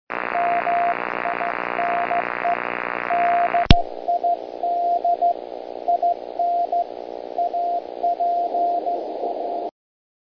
A brief file, showing CW first with a bad electrical noise, then the filter switched in.  Again the noise is not removed but is considerably attenuated and copy becomes more pleasant.